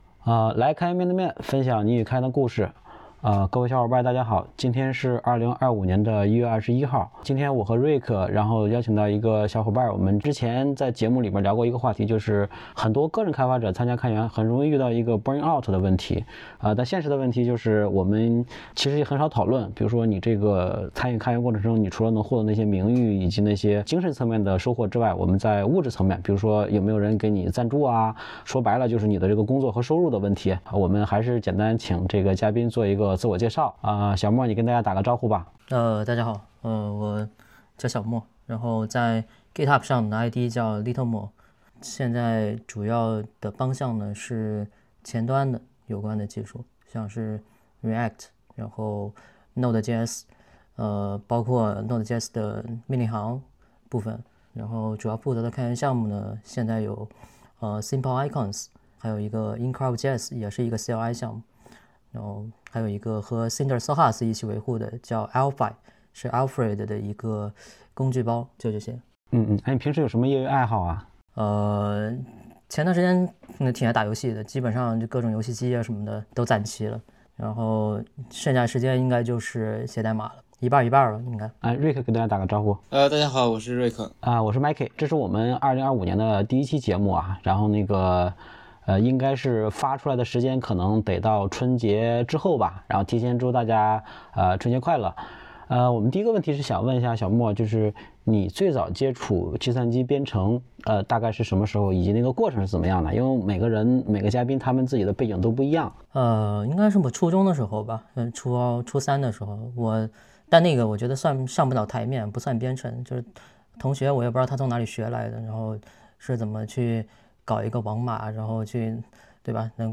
嘉宾